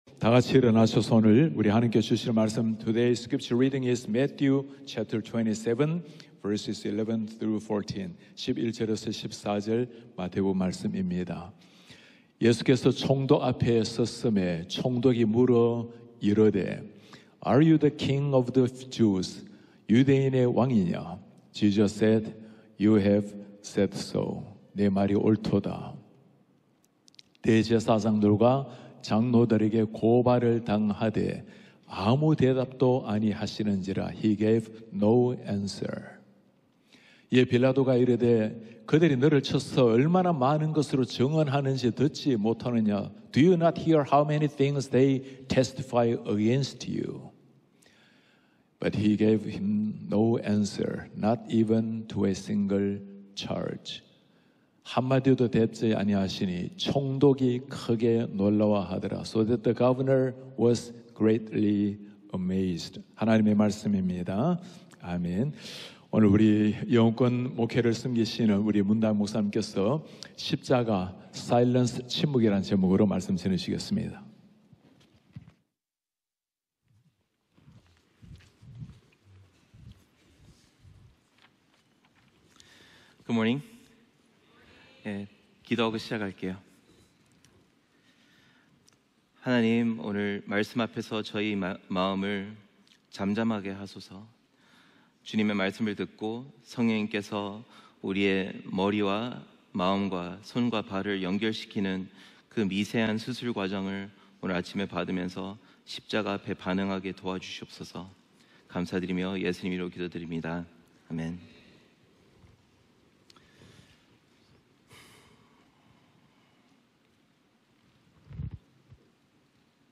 예배: 특별 집회